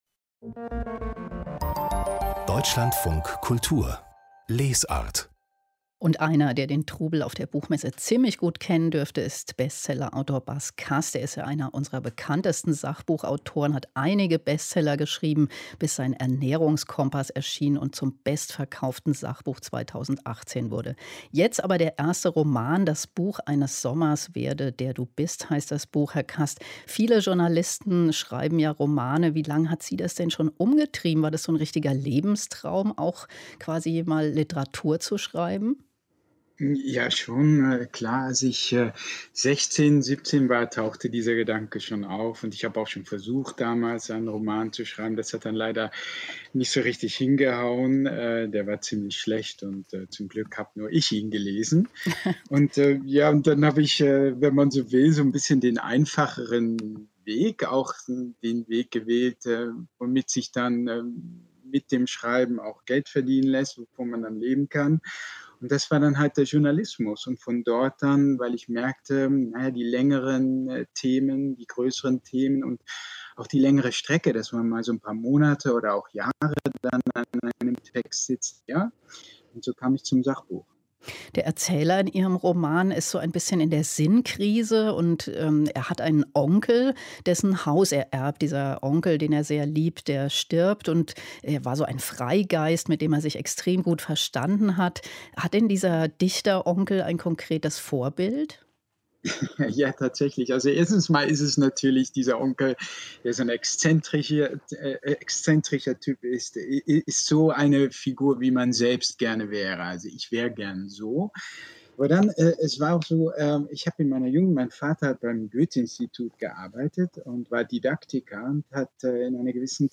dlfk- Gespräch mit dem Autor